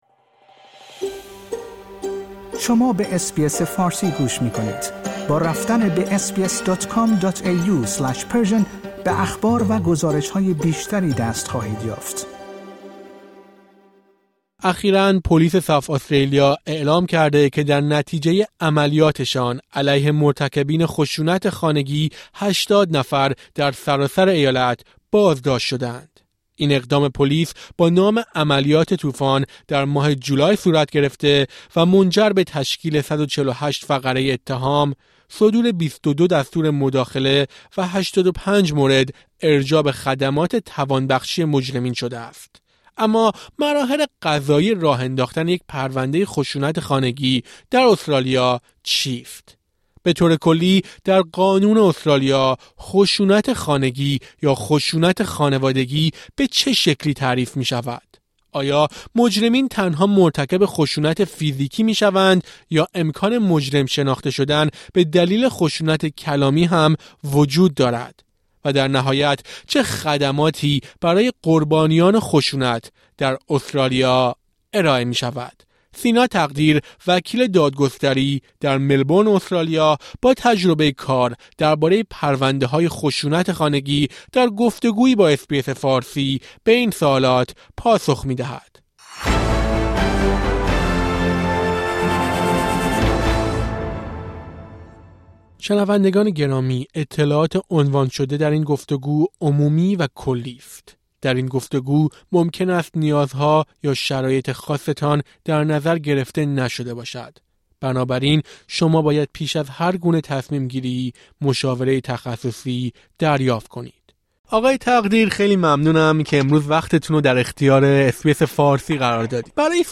در گفت‌وگویی با اس‌بی‌اس به این سوالات پاسخ می‌دهد.